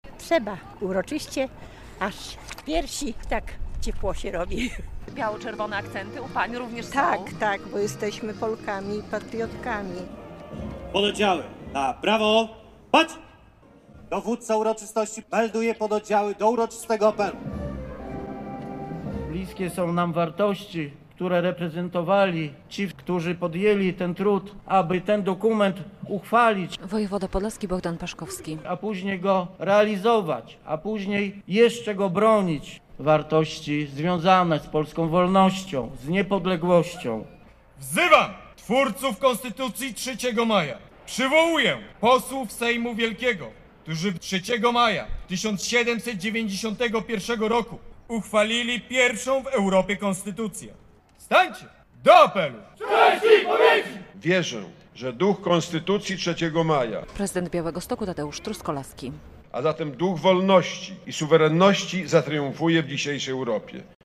Obchody 231. rocznicy uchwalenia Konstytucji 3 Maja w Białymstoku